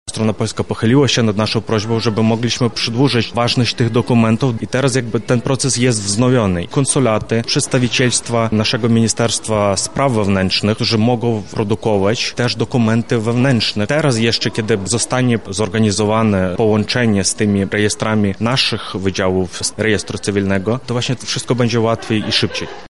• mówi Oleh Kuts, Konsul Generalny Ukrainy w Lublinie.